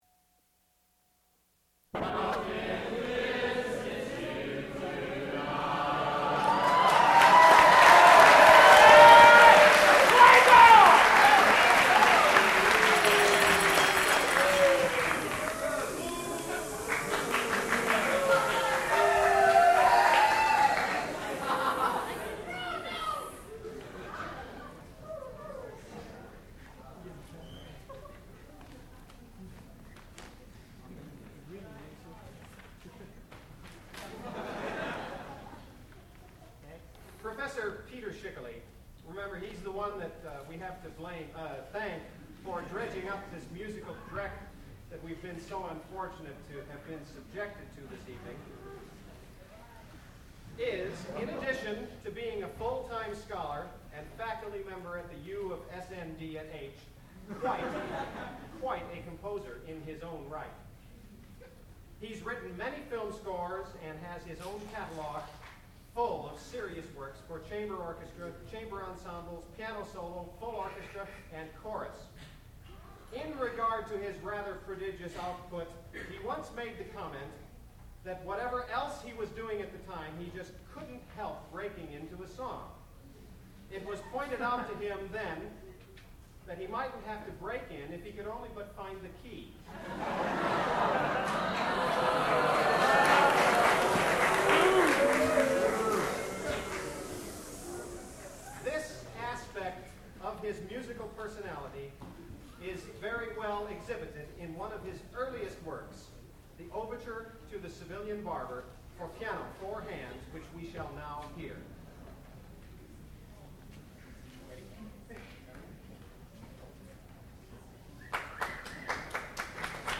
Overture to "The Civilian Barber", for piano, 4 hands
sound recording-musical
classical music